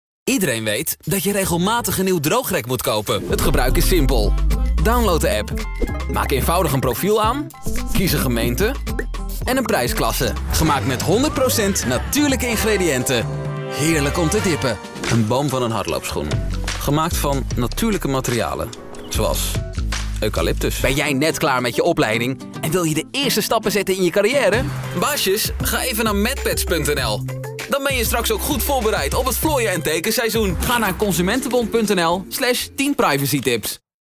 Der hollĂ€ndische Voice-Over tĂ¶nt frisch und begeistert. Seine Stimme wirkt jung, aber meist reif, so ideal fĂŒr jede Altersgruppe.
Sein Heimstudio hat die beste KlangqualitĂ€t, auch auf Grund der Anwesenheit einer Sprechkabine.
Sprechprobe: Sonstiges (Muttersprache):